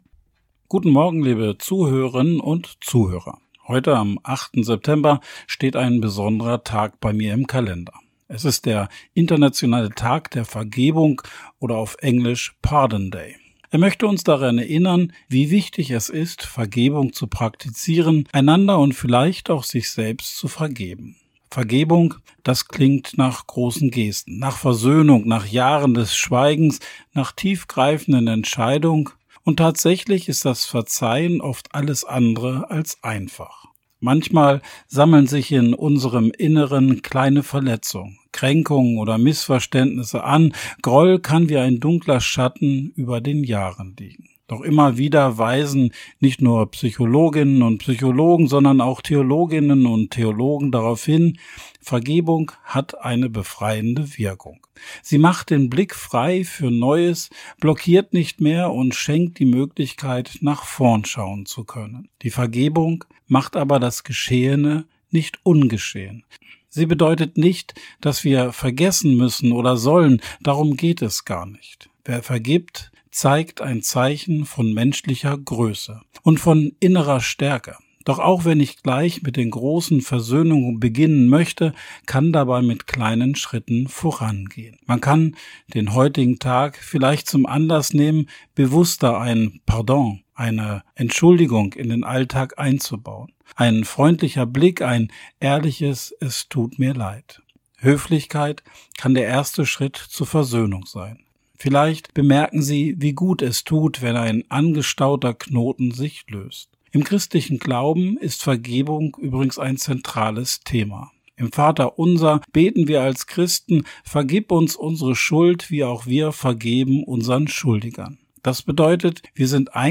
Radioandacht vom 8. September